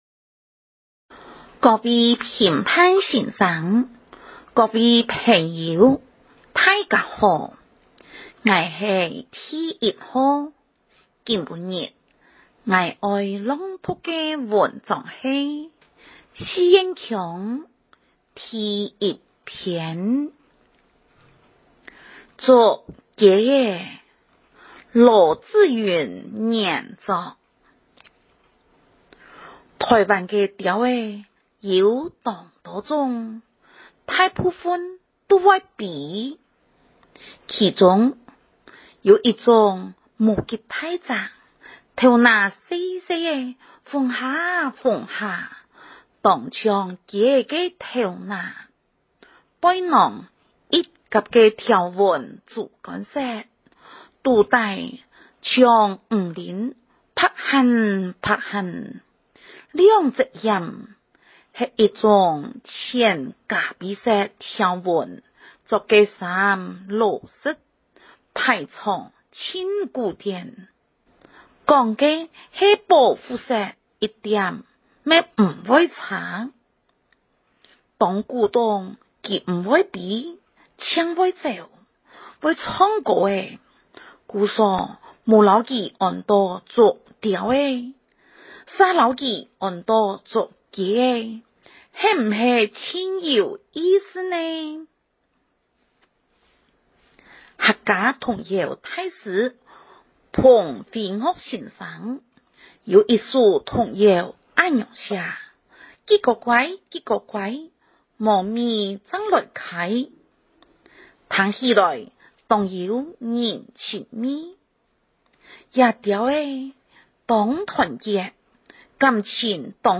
108學年度校內多語文競賽-客家語朗讀組錄音音檔下載
108學年度校內多語文競賽-客家語朗讀組錄音音檔下載 {{ $t('FEZ002') }} 活動訊息 | 108學年度校內多語文競賽 客家語朗讀比賽文章：竹子雞 歡迎參賽選手下載錄音檔練習！ {{ $t('FEZ012') }} 客家語朗讀文章-竹雞仔.mp3 另開新視窗 {{ $t('keywords') }} 108學年度校內多語文競賽-客家語朗讀組錄音音檔下載 {{ $t('FEZ003') }} Invalid date {{ $t('FEZ005') }} 636 |